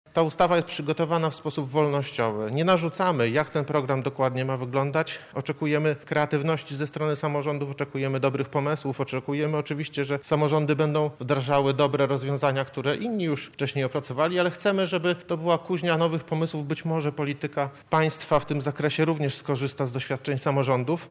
Poseł PiS Piotr Uściński mówił podczas konferencji prasowej w Sejmie, że każdy samorząd będzie mógł kształtować wspomniany program dobrowolnie.